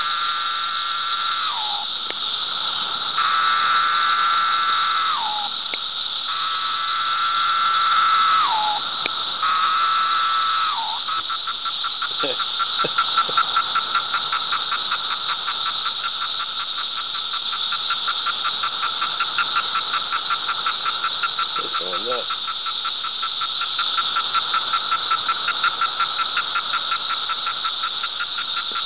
“Decims” aka Pharaoh Cicadas
Their songs are very similar, however, when M. neotredecim & M. tredecim emerge in the same location, M. neotredecim’s song takes a higher pitch. Sounds like “Pharaoh, Pharaoh!”.
M-septendecim-court2-3.mp3